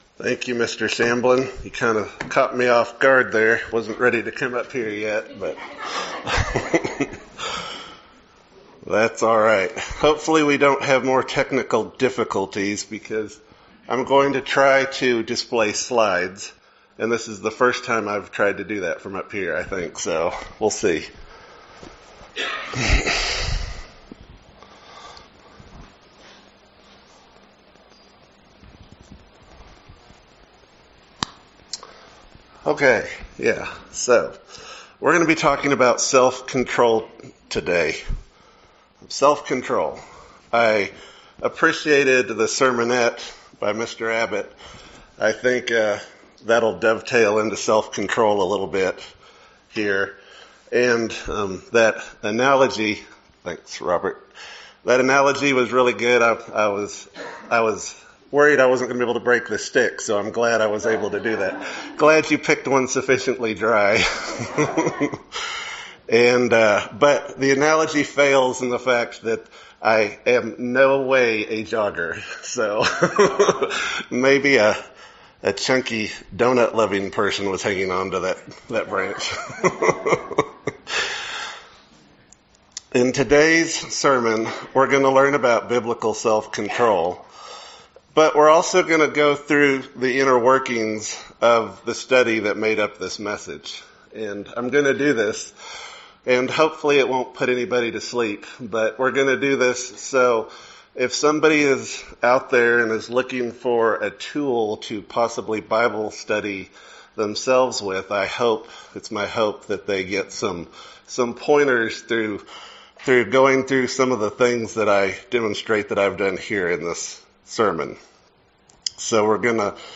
The topic of self-control is covered in this sermon.
Given in Lubbock, TX